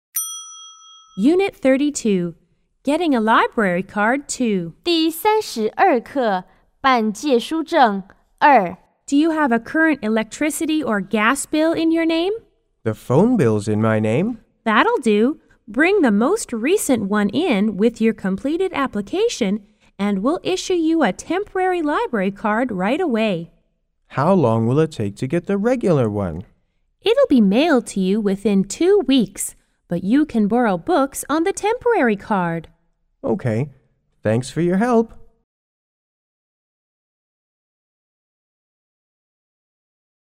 L= Librarian B= Borrower